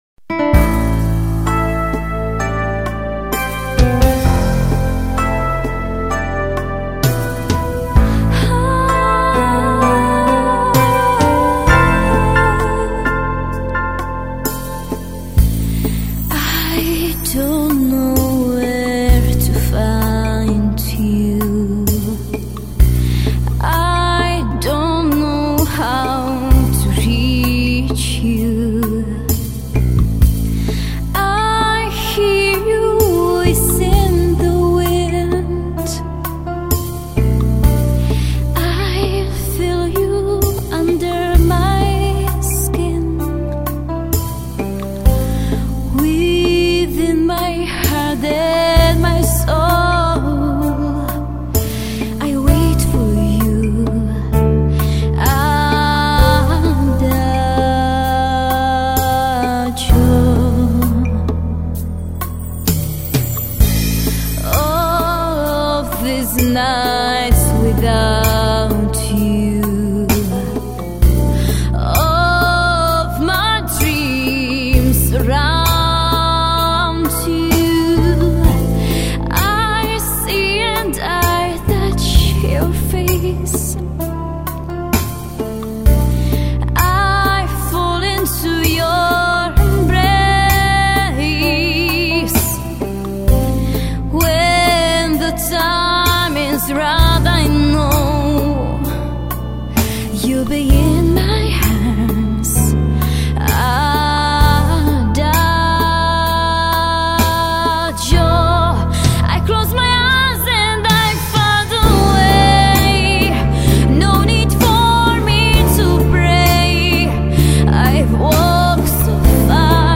Žánr: Pop.